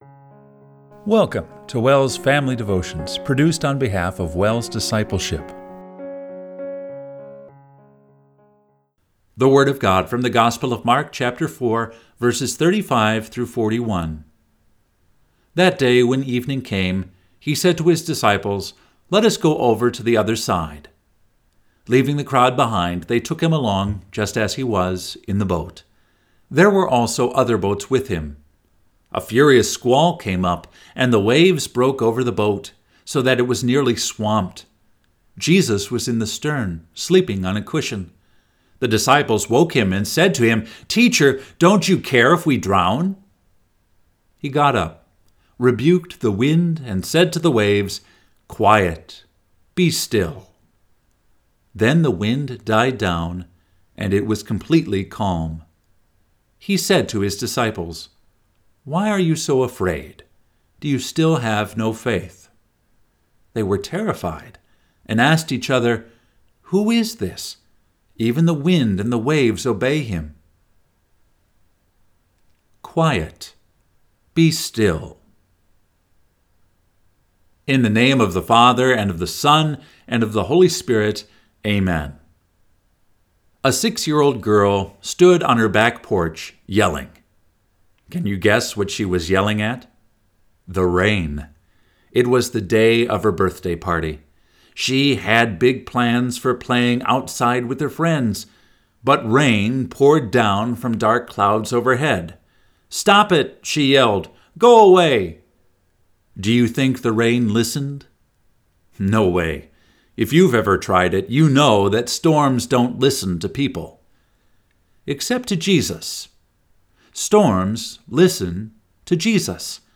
Family Devotion – June 28, 2024